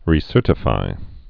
(rē-sûrtə-fī)